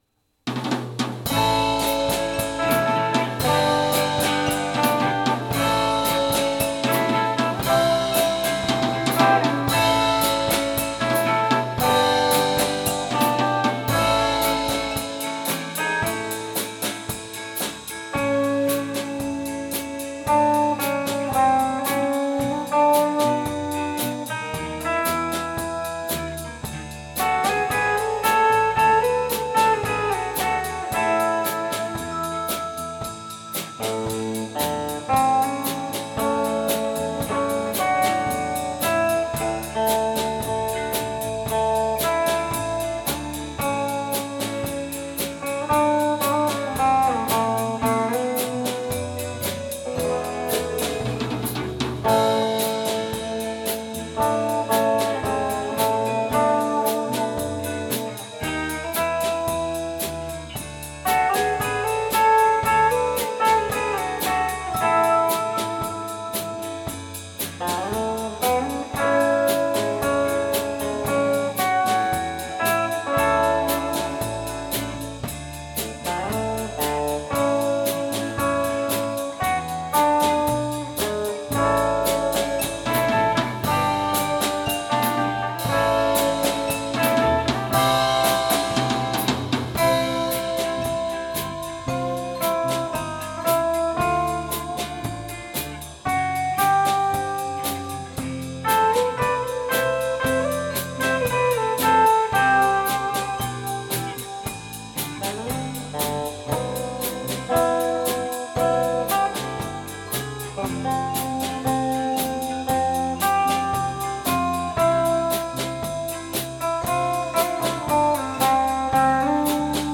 2019 X’mas Live クリスマスソング